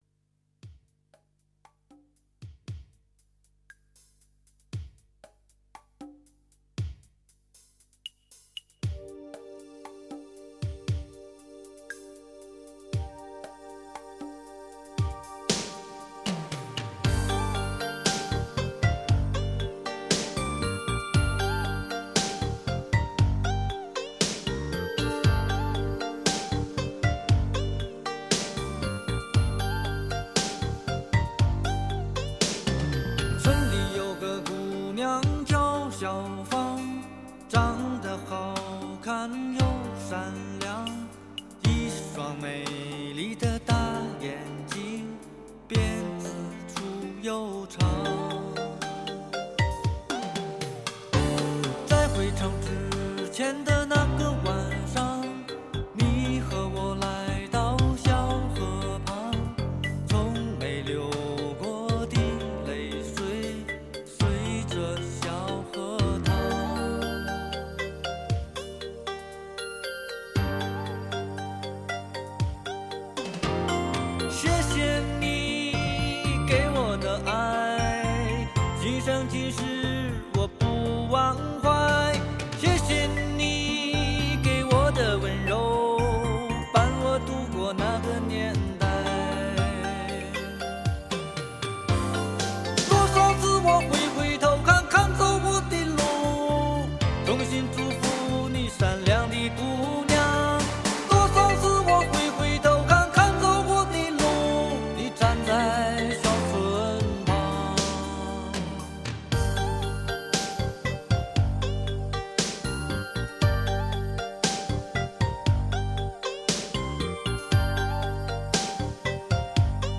著名创作型歌手 “城市民谣”代言人
声音特点朴实 平和 歌曲旋律易记 流畅